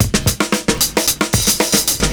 112FILLS04.wav